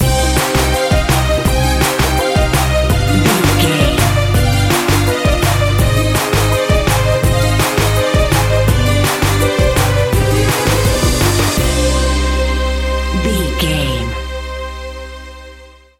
Aeolian/Minor
Fast
World Music
percussion
congas
bongos
djembe